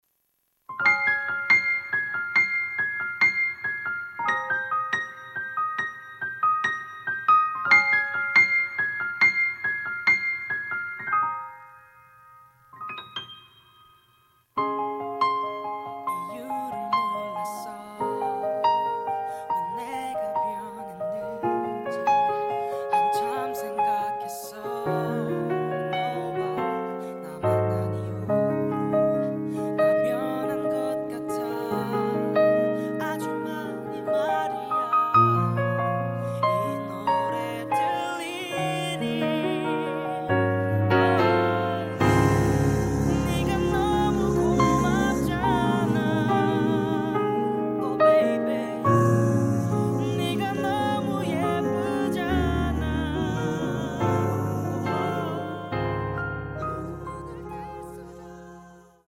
음정 원키 3:19
장르 가요 구분 Voice MR